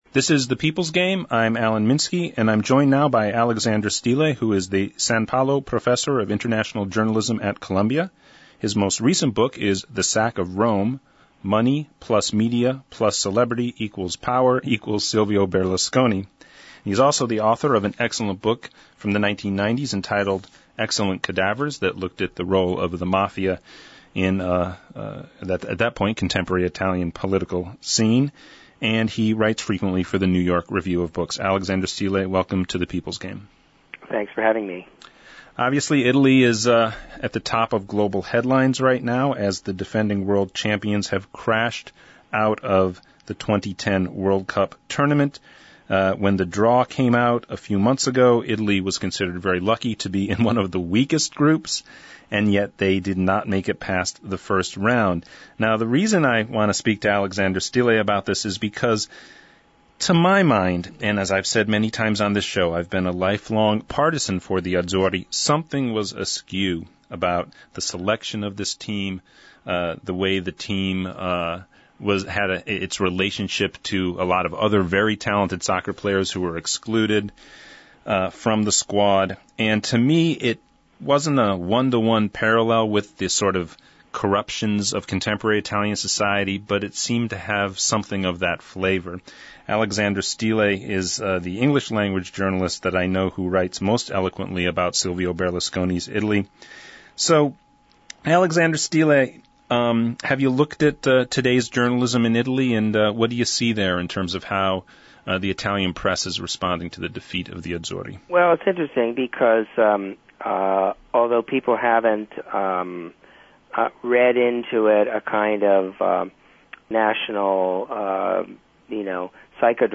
The People’s Game Radio – Alexander Stille Interview